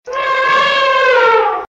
Gajah_Suara.ogg